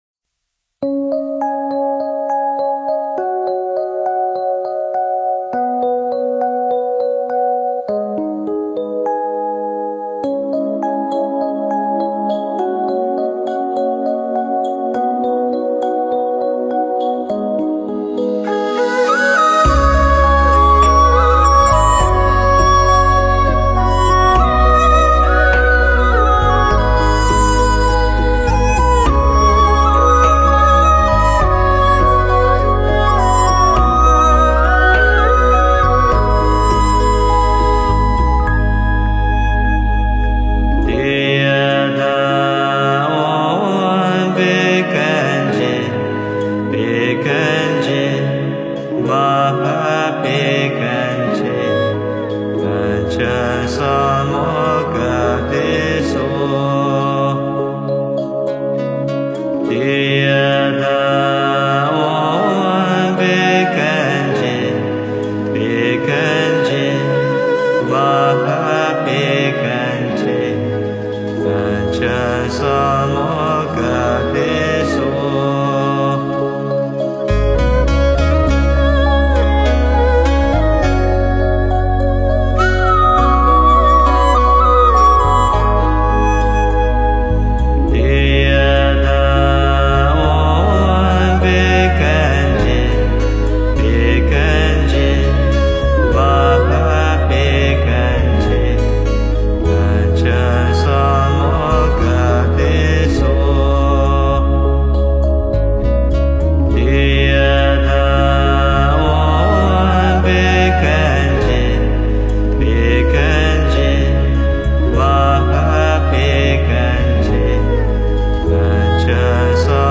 佛音 诵经 佛教音乐 返回列表 上一篇： 药师佛心咒 下一篇： Aad Guray Nameh